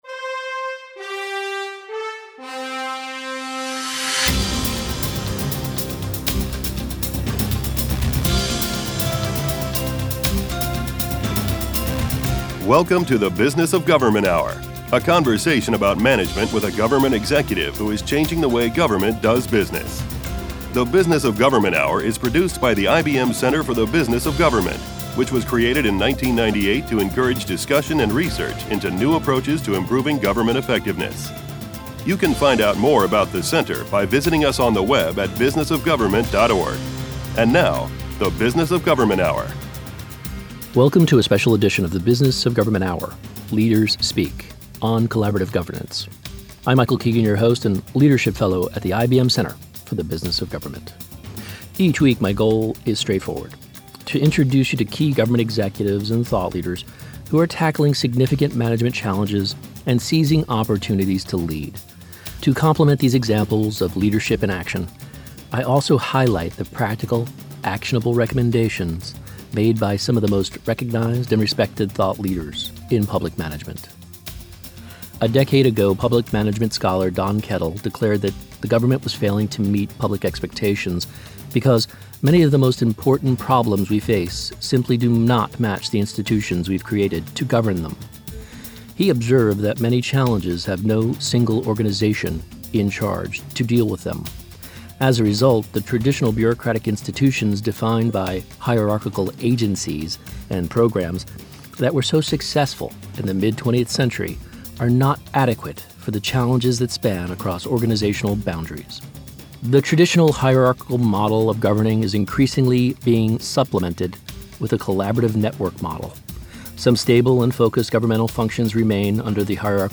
Radio Hour Share Podcast TV Hour The Business of Government Hour Stay connected with the IBM Center Download or Email Listen to the Business of Government Hour Anytime, Anywhere Video not available